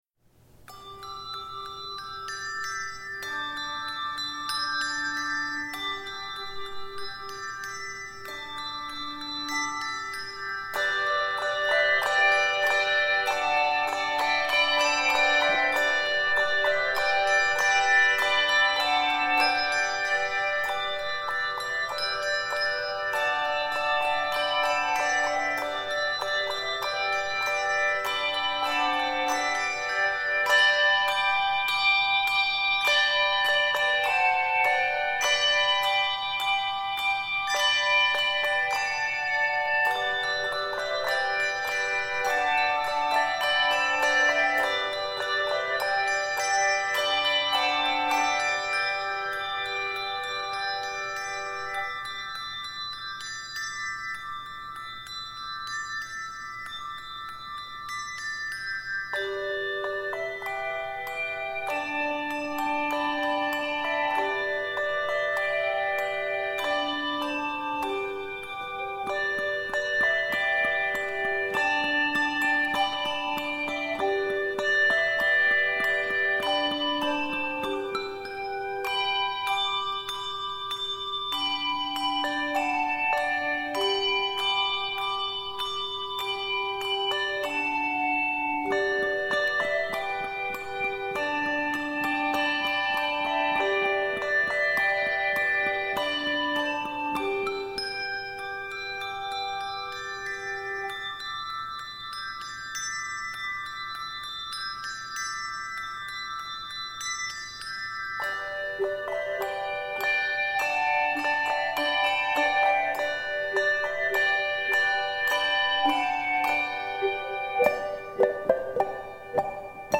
American folk hymn
It is set in G Major.